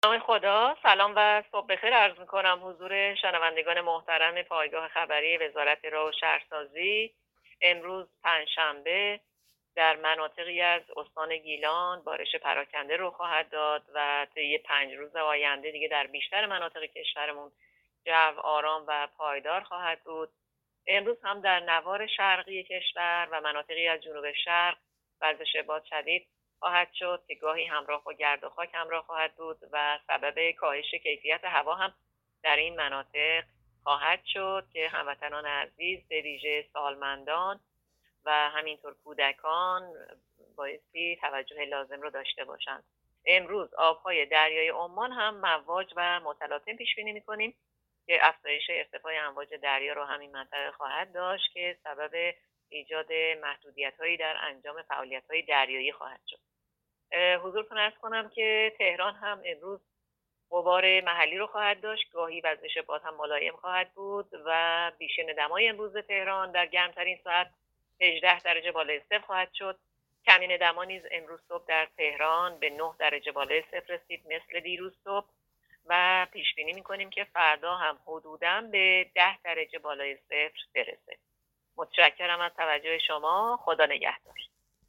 گزارش رادیو اینترنتی پایگاه‌ خبری از آخرین وضعیت آب‌وهوای پانزدهم آبان؛